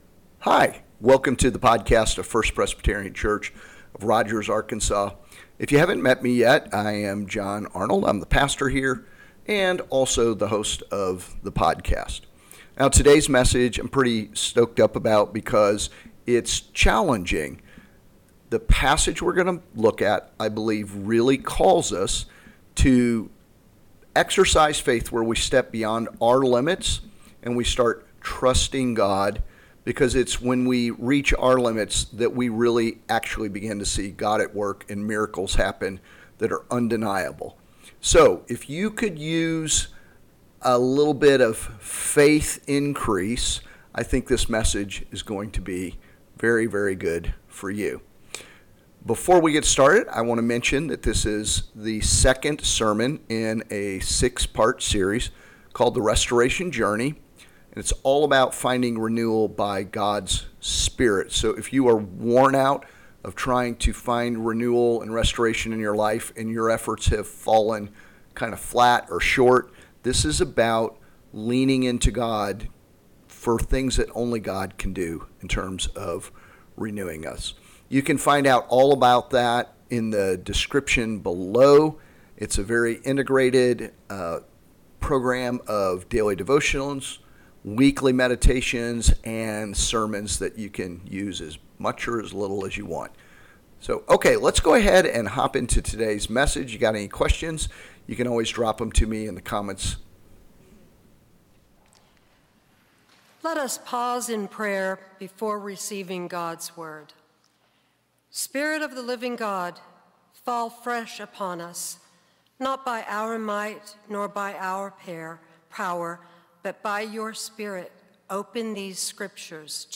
God Exceeds Our Limits – Trusting God for the impossible. Part 2 of The Restoration Sermon Series